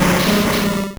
Cri de Smogo dans Pokémon Or et Argent.